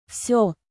Звуки со словом Всё
Женский голос решает всё